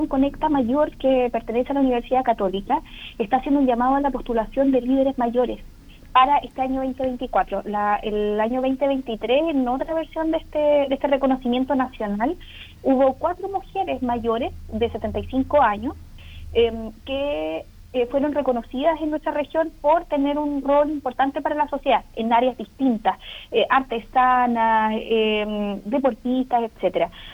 La Seremi de Gobierno en la Región de Los Lagos, Danitza Ortiz, destacó esta iniciativa, donde en la convocatoria anterior se reconoció el trabajo de cuatro lideresas del territorio.